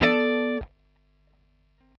Bm7_8.wav